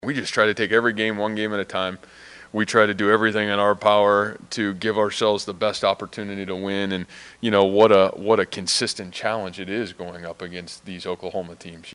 That’s ISU coach Matt Campbell who says it takes a 60 minute effort to beat the Sooners.